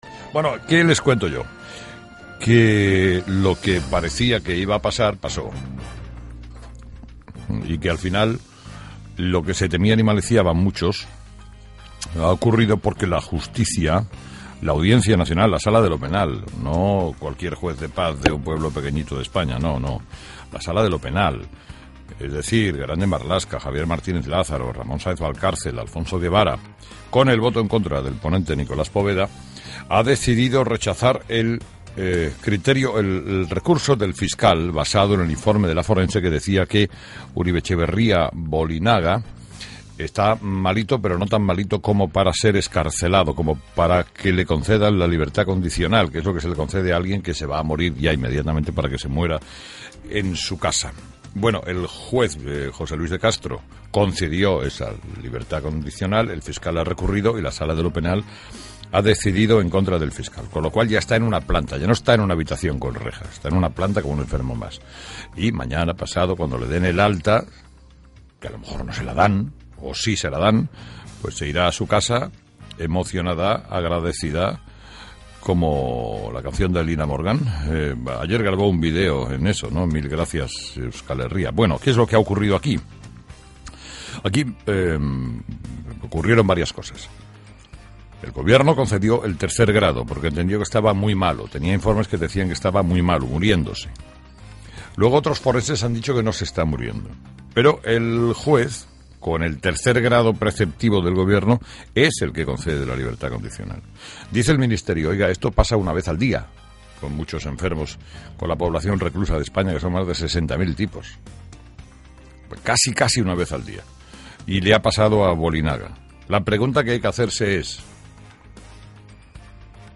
13/09/2012 Editorial de Herrera: ‘Si Bolinaga no muere, el Gobierno estará en un lío’